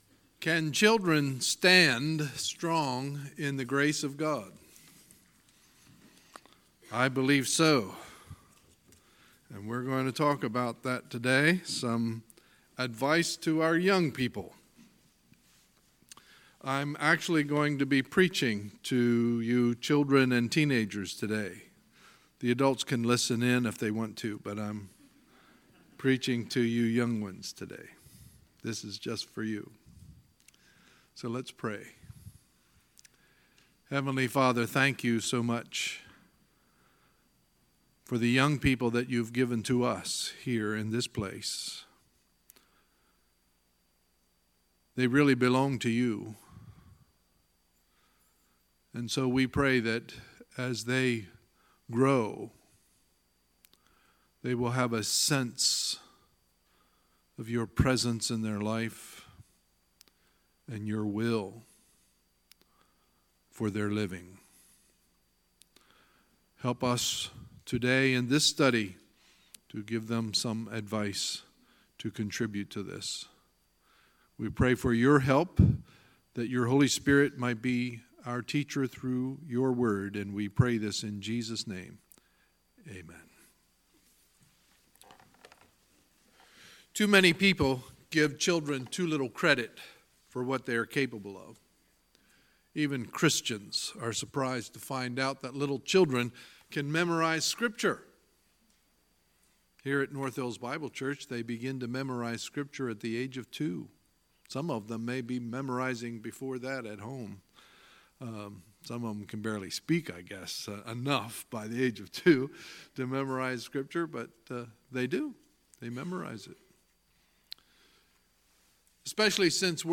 Sunday, June 10, 2018 – Sunday Morning Service